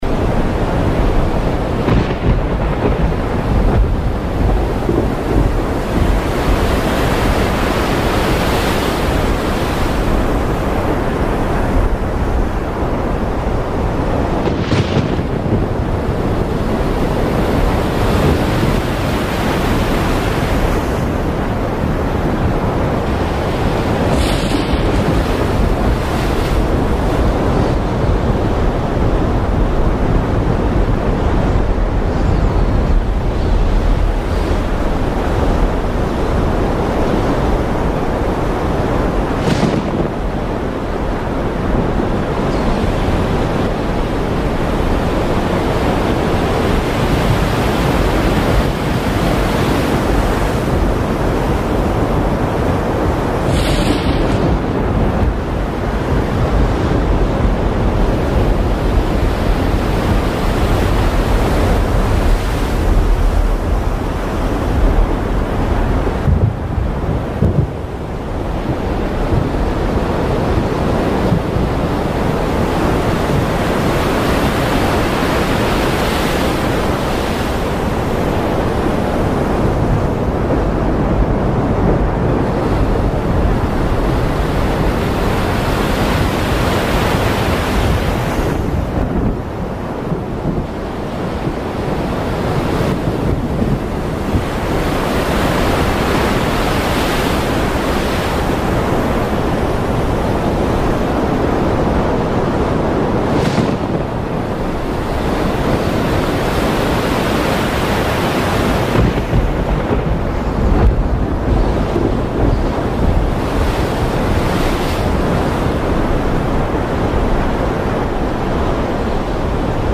Звуки шторма
Шторм на море с грозой